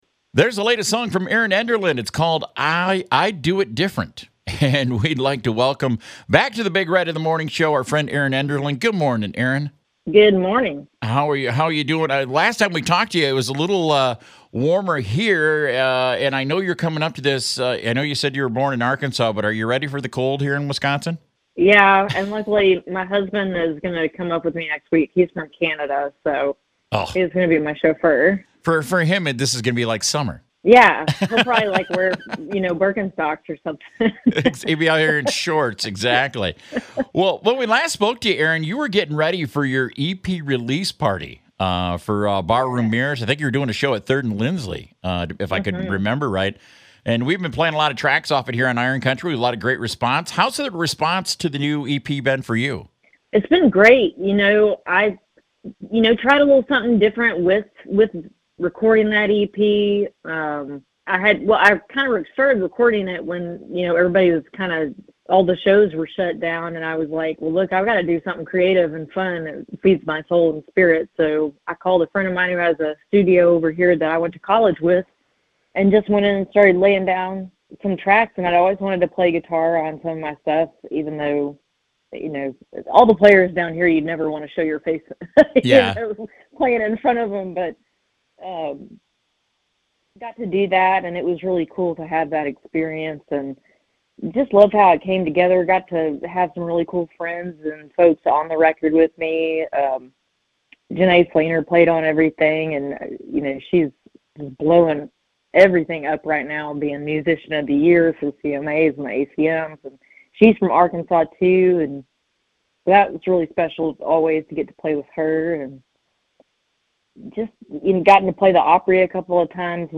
Erin Enderlin Interview